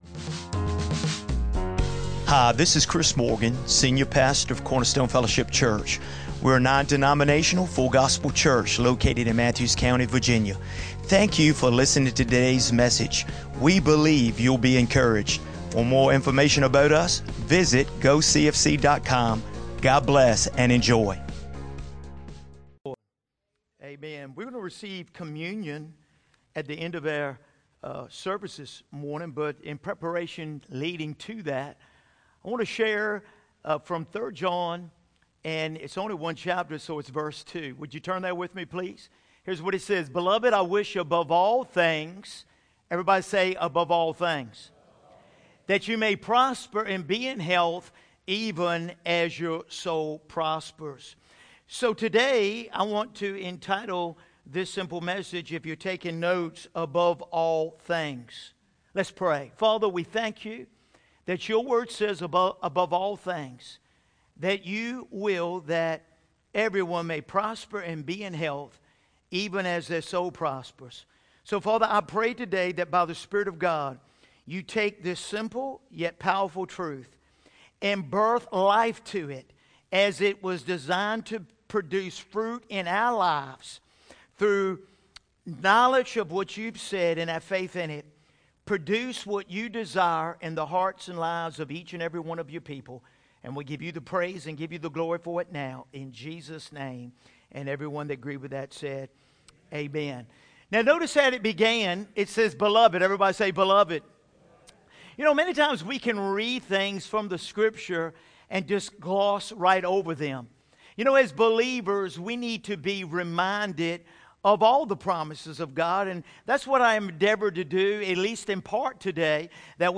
2024 Sunday Morning Scripture References